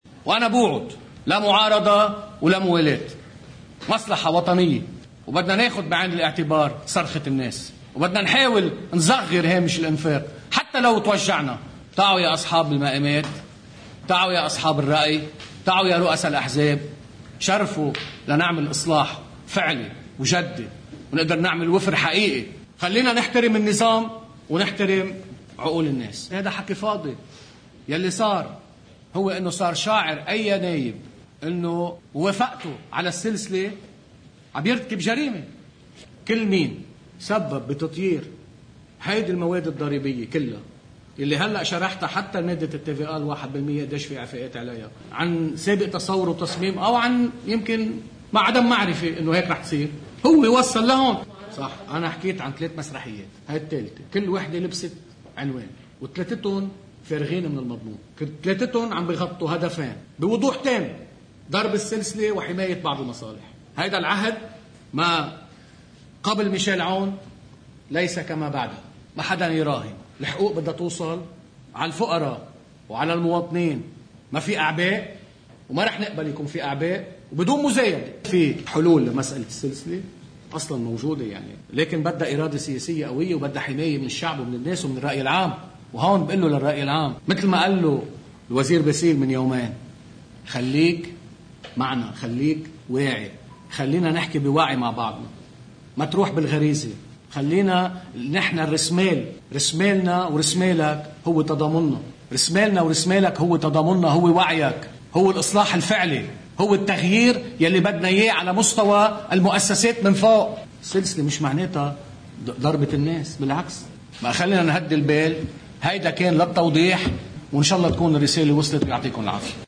مقتطف من حديث رئيس لجنة المال والموازنة النائب كنعان حول مسرحية “الحراك المدني“: (الجزء 3)